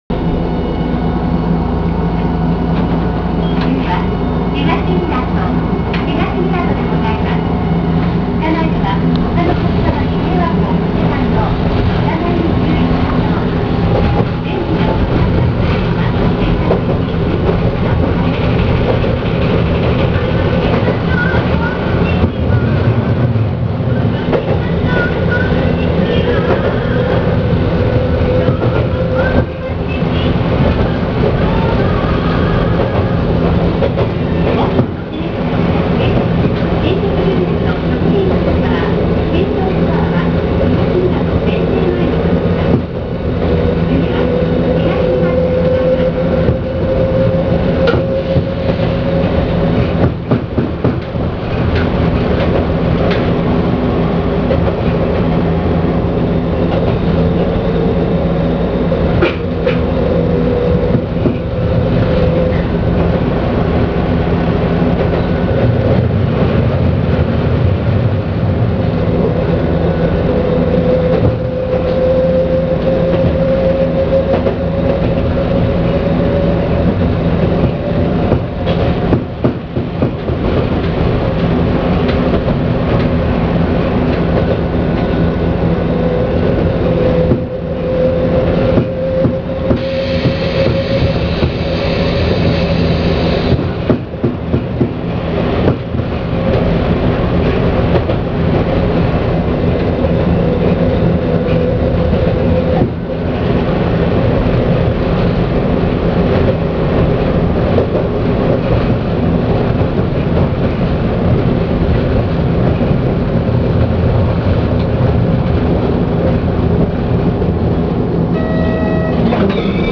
・モ701形走行音
【阪堺線】石津〜東湊（2分3秒：607KB）…705号にて
ごく普通の走行音。抵抗制御です。
途中で聞こえる歌は広告です。